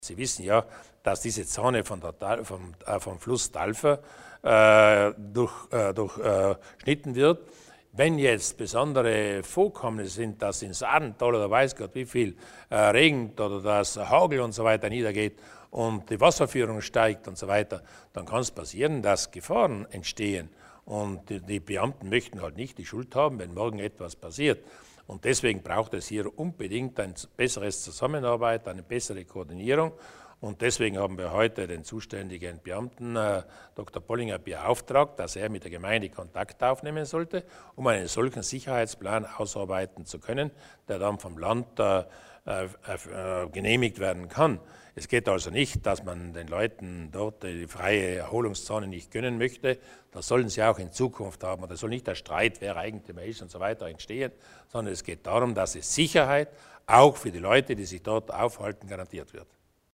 Landeshauptmann Durnwalder über die Maßnahmen zur Sicherheit auf den Talferwiesen